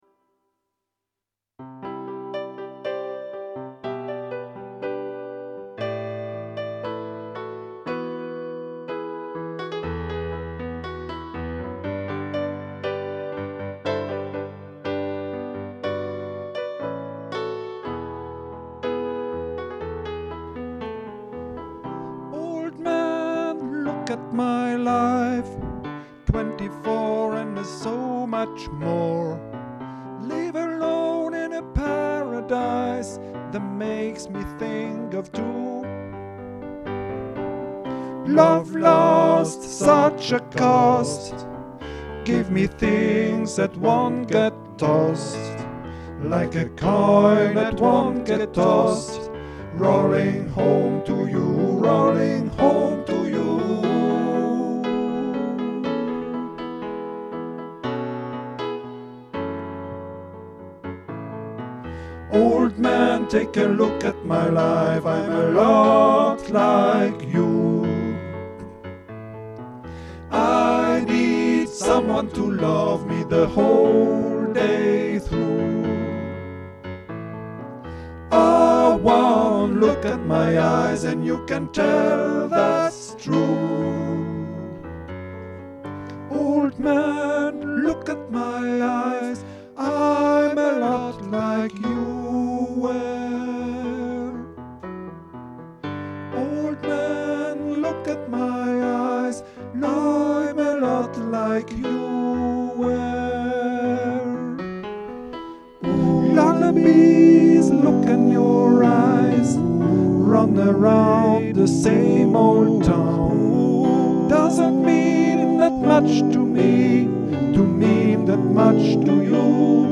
fichier Audio de travail des Voix avec piano